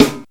Snares
11___SNR.WAV